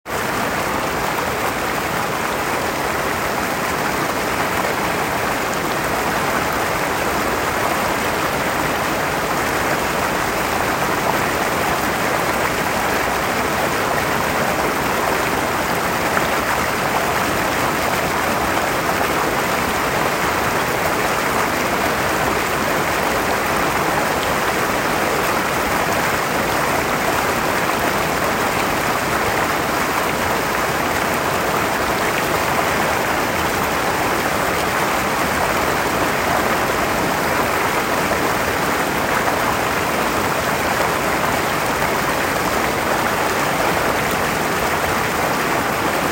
Cocora Valley River
On our way back we stopped at this river to cool down and have an Oreo snack break.
cocora_valley_river.m4a